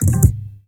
DRUMFILL09-L.wav